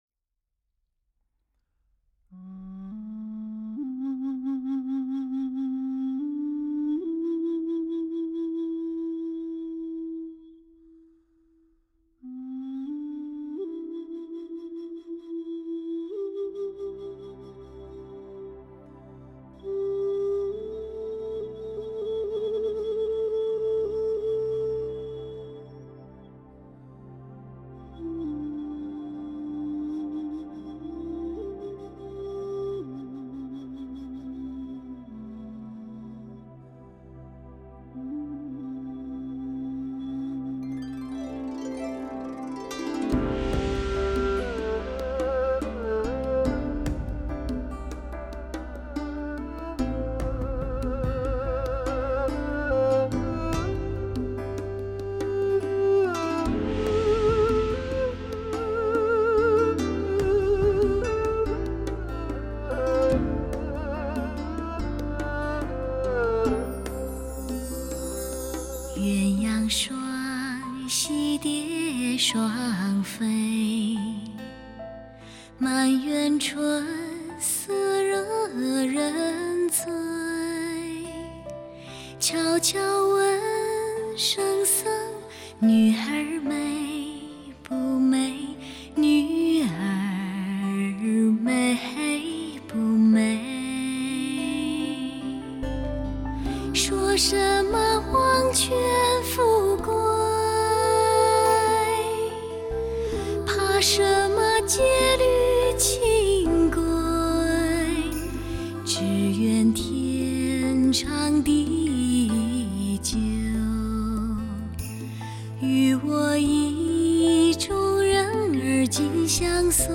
唯美天籁之声，深情刻画出感悟、感性的心声。略带沙哑的性感嗓音恣情
挥晒，歌声与器乐于巧夺天工的辉映中激荡出惊艳四座的动人乐音。